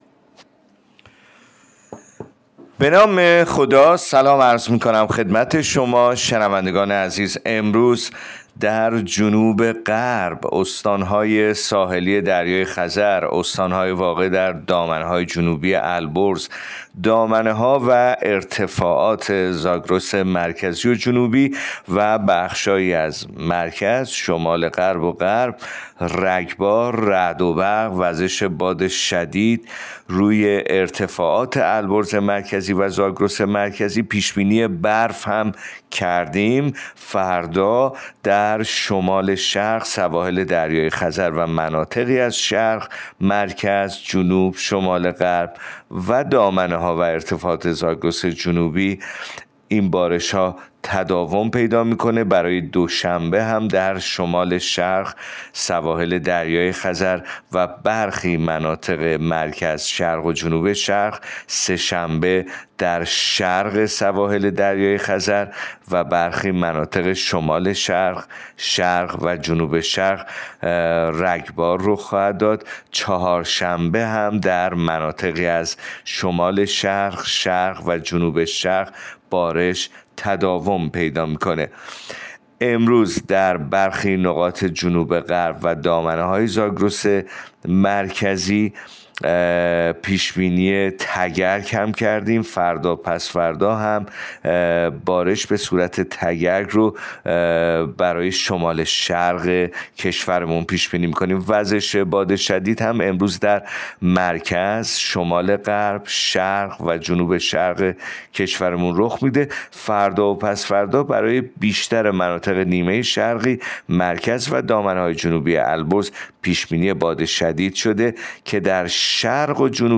گزارش رادیو اینترنتی پایگاه‌ خبری از آخرین وضعیت آب‌وهوای ۲ فروردین؛